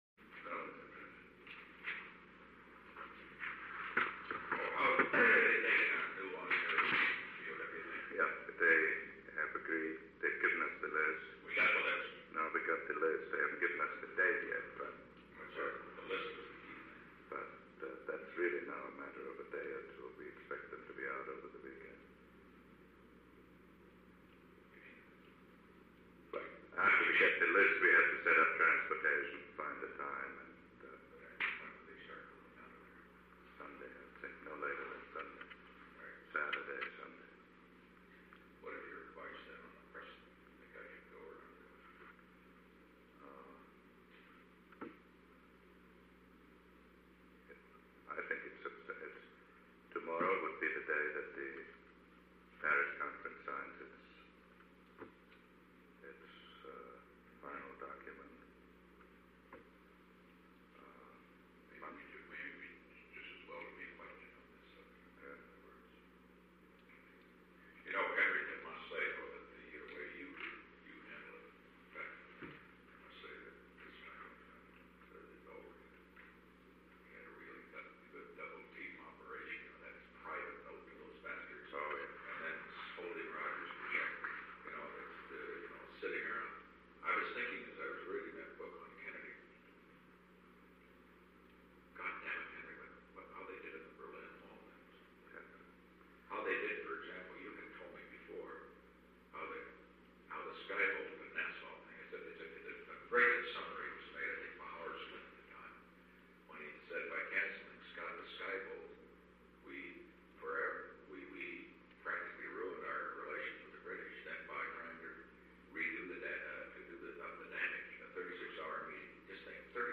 Conversation No. 866-4 Date: March 1, 1973 Time: 9:47 am – 10:44 am -3- NIXON PRESIDENTIAL LIBRARY AND MUSEUM Tape Subject Log (rev. May-2010) Conversation No. 866-4 (cont’d) Location: Oval Office The President met with Henry A. Kissinger.
Secret White House Tapes